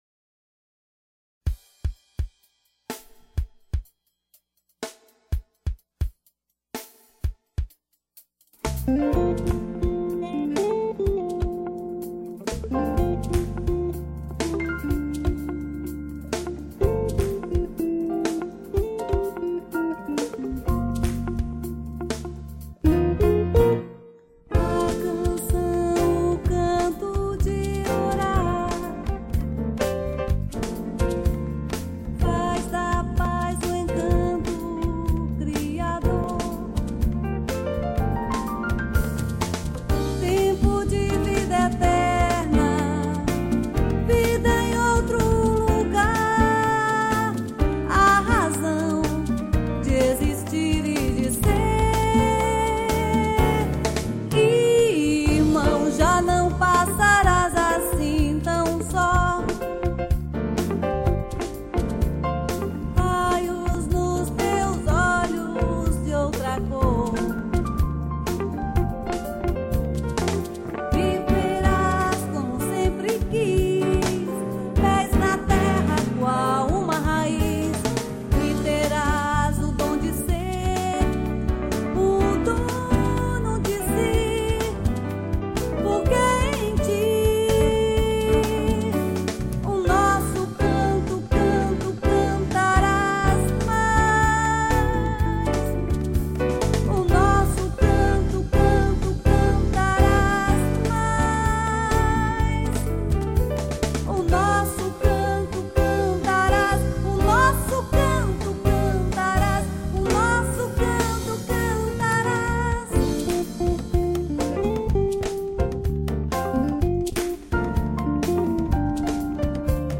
4   04:12:00   Faixa:     Canção
Bateria
Piano Acústico, Teclados
Baixo Elétrico 6
Guitarra
Congas
Agogo, Pandeiro, Triângulo, Surdo